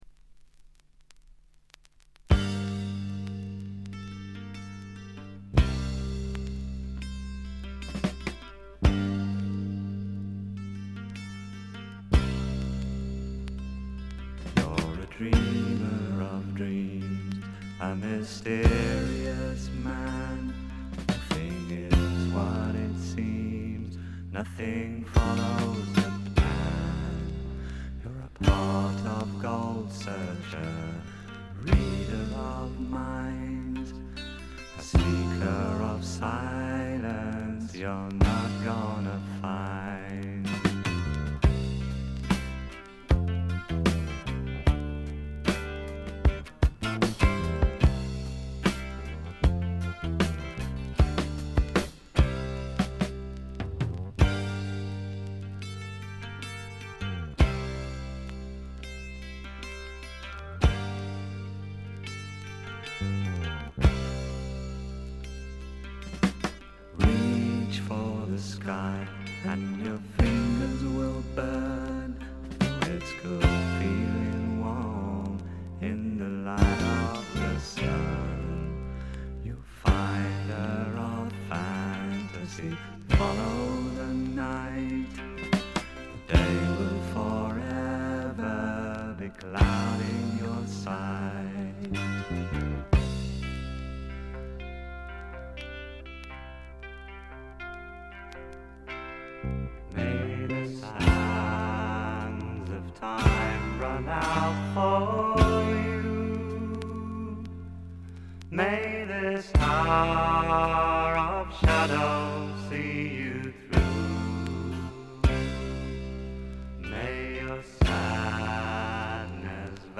静音部で軽微なチリプチが聴かれますが鑑賞に影響するようなノイズはありません。
ドラムとベースがびしばし決まるウルトラグレートなフォーク・ロックです。
試聴曲は現品からの取り込み音源です。